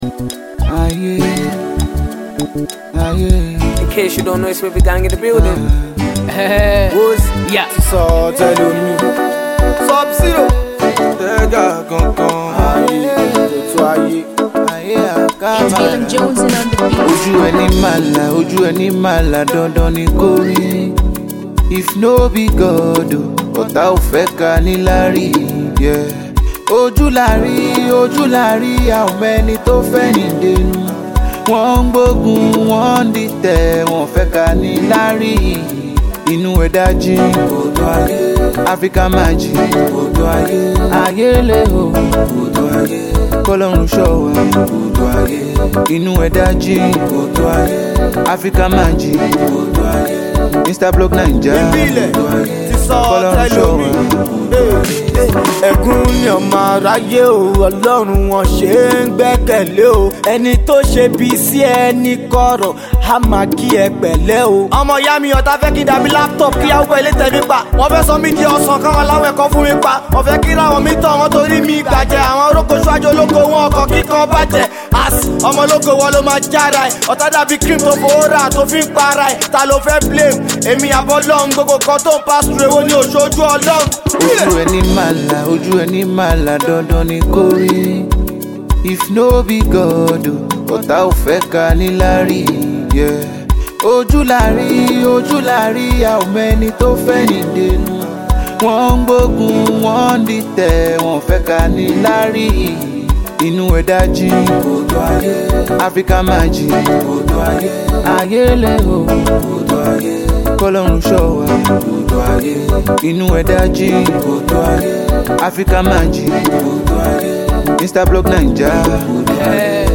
Home Afro-pop Audio
Young Nigerian Afro-pop singer
collaborative posse track
an array of upcoming and talented street rappers